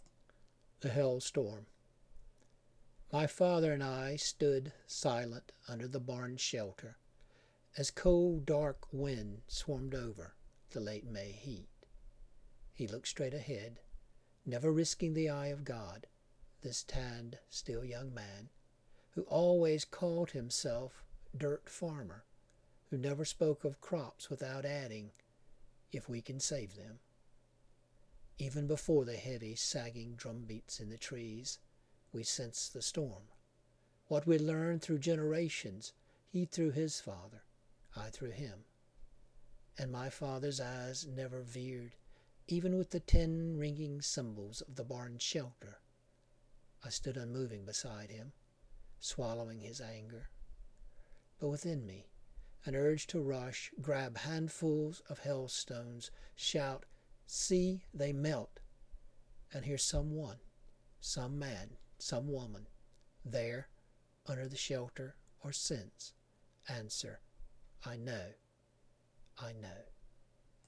The Hailstorm (Reading)
The Hailstorm} for a reading of this poem.